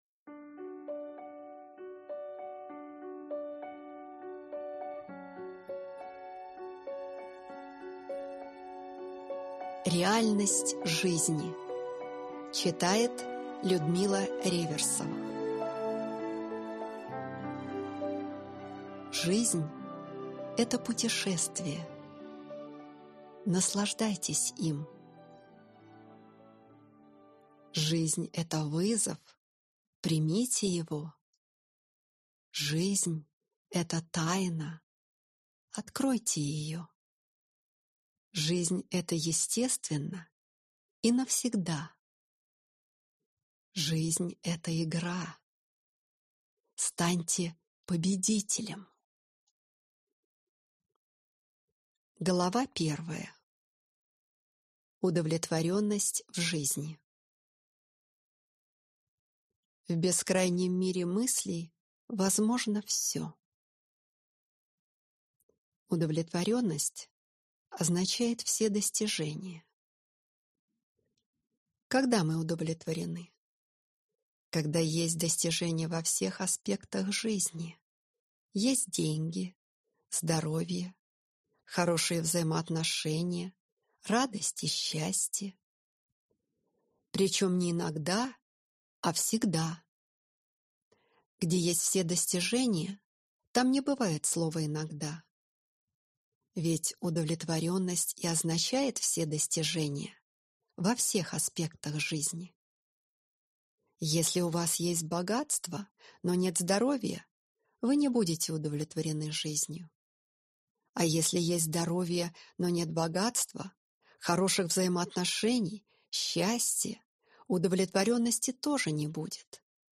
Аудиокнига Реальность жизни | Библиотека аудиокниг
Прослушать и бесплатно скачать фрагмент аудиокниги